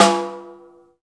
Index of /90_sSampleCDs/Roland L-CDX-01/SNR_Rim & Stick/SNR_Rim Modules
SNR RIM - 0G.wav